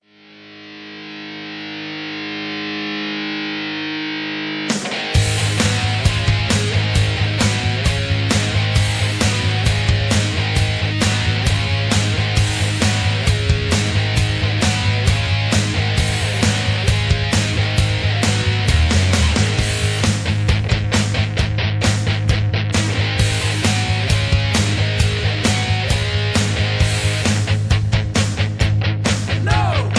karaoke, mp3 backing tracks
rap, rock